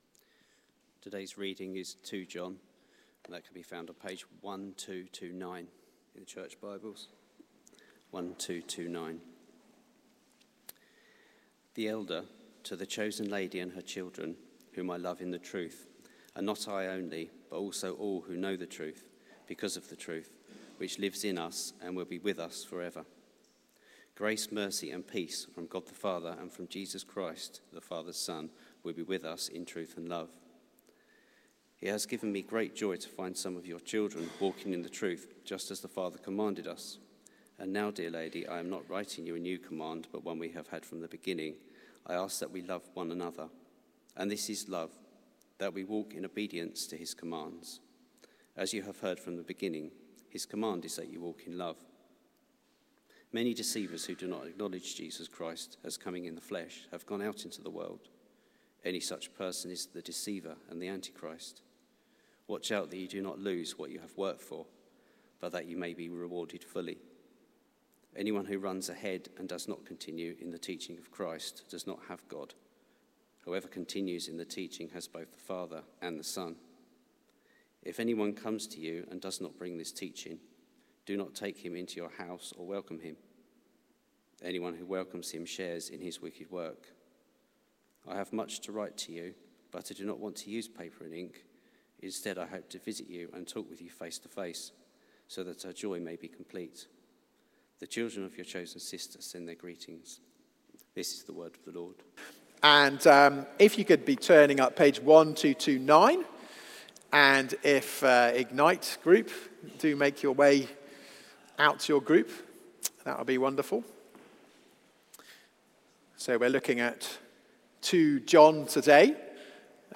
The Fight for the Faith (John and Jude) Theme: Walk in Obedience Sermon